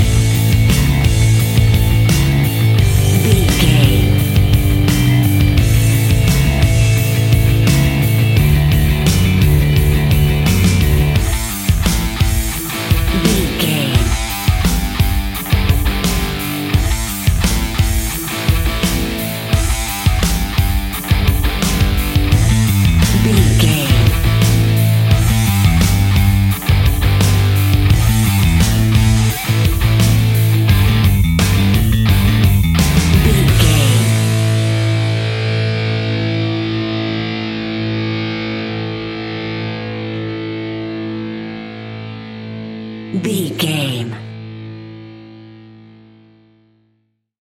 Epic / Action
Fast paced
Aeolian/Minor
hard rock
blues rock
distortion
instrumentals
rock guitars
Rock Bass
Rock Drums
heavy drums
distorted guitars
hammond organ